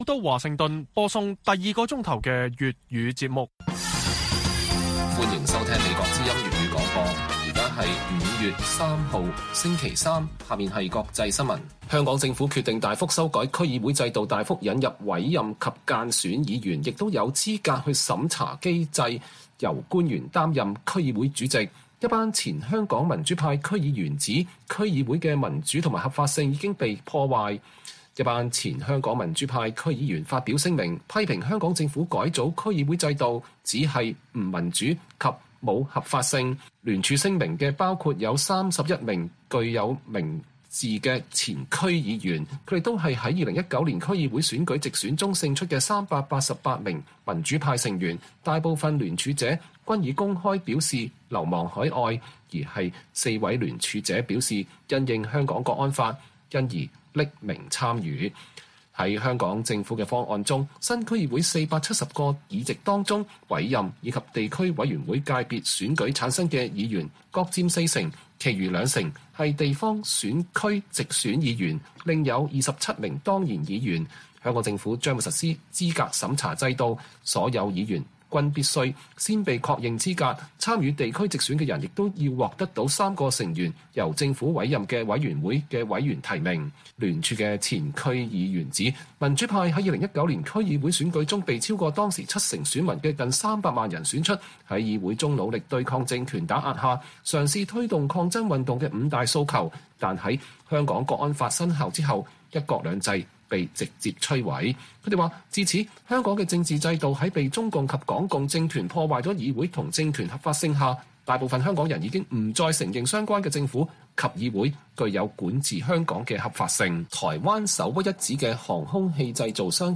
粵語新聞 晚上10-11點: 前香港民主派區議員聯署批評區議會制度不民主及沒合法性